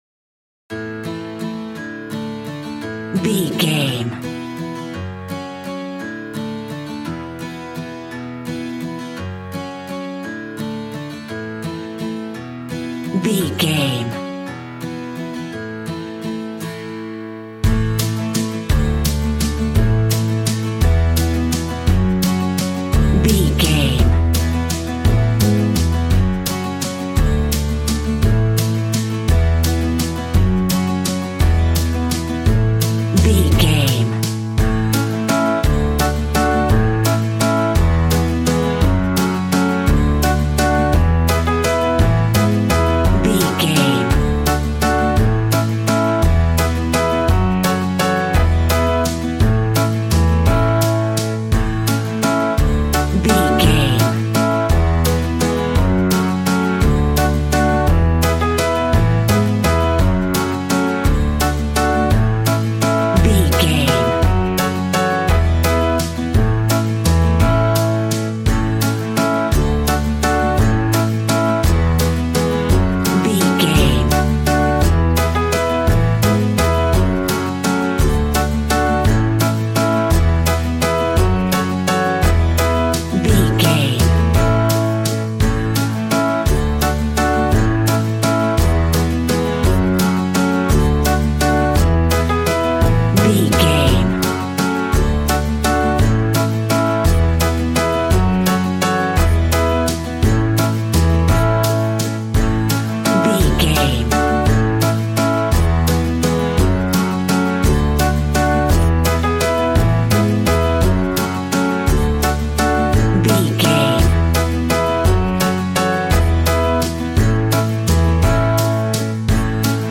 Upbeat, uptempo and exciting!
Ionian/Major
fun
cheerful/happy
bouncy
electric piano
electric guitar
drum machine